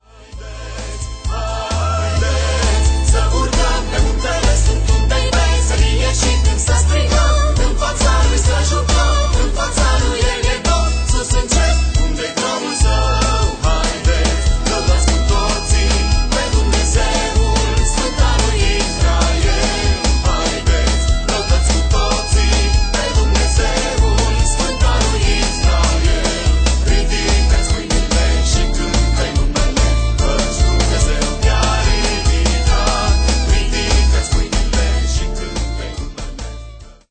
Un alt album de lauda si inchinare